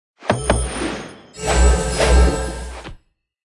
Media:anim_kitsune witch.wav 动作音效 anim 查看其技能时触发动作的音效
Anim_kitsune_witch.wav